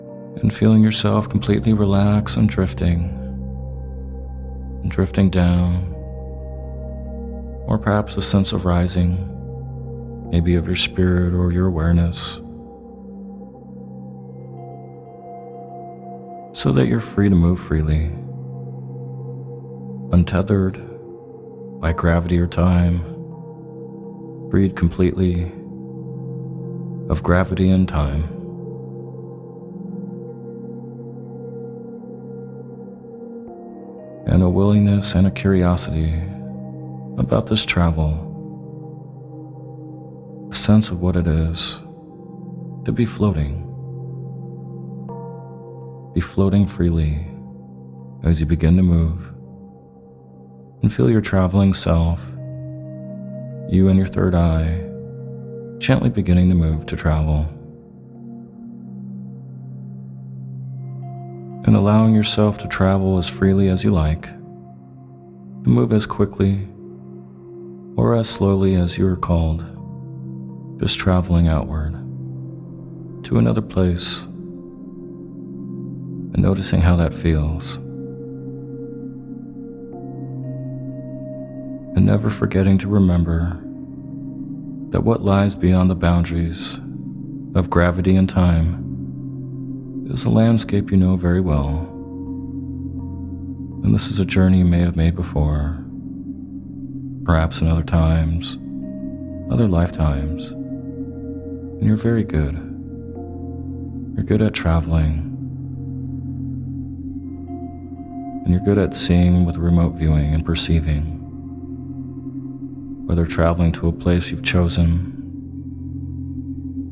Sleep Hypnosis For Remote Viewing and Having O.B.E's
In this sleep hypnosis audio, you’ll be given mental imagery and suggestions for both remote viewing and O.B.E’s.